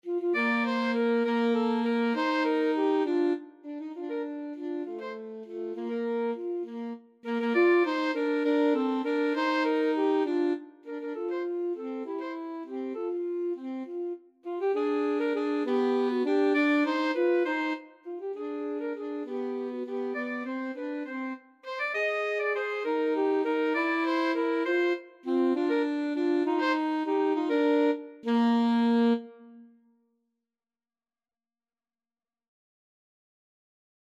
3/8 (View more 3/8 Music)
Classical (View more Classical Alto Saxophone Duet Music)